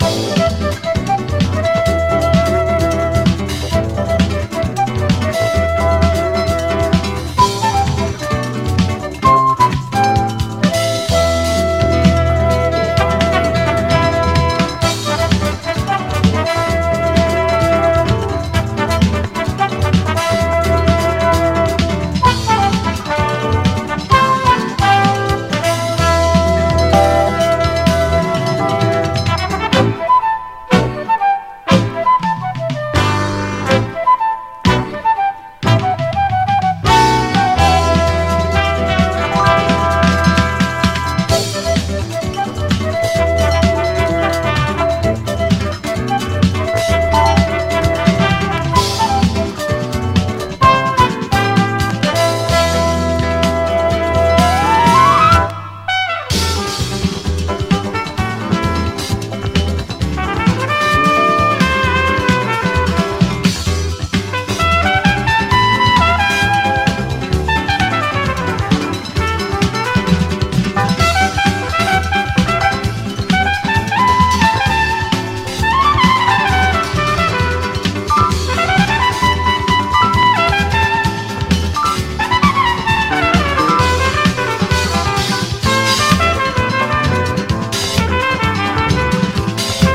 JAPANESE FUSION / BRAZILIAN FUSION
和モノ・ブラジリアン・フュージョン傑作！
アーバン感漂う軽快なシティ・サンバ